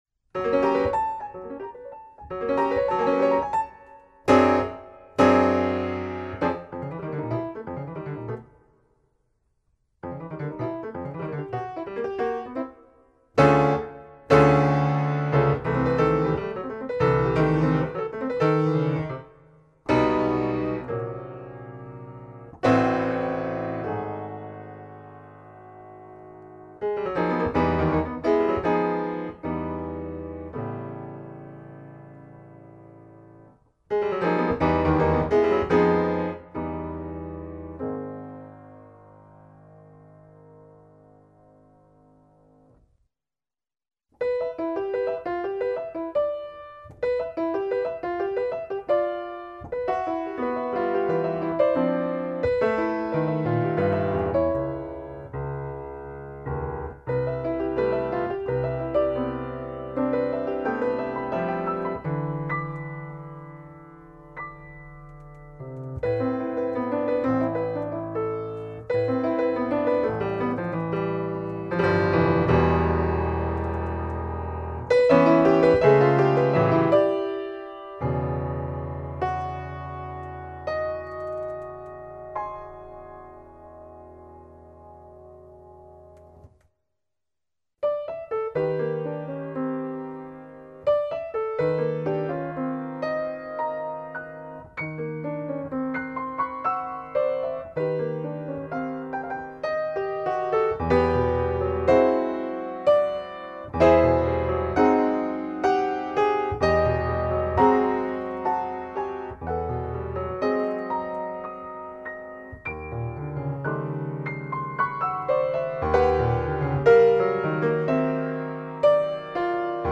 mezzo di esecuzione: pianoforte